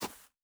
Shoe Step Grass Medium A.wav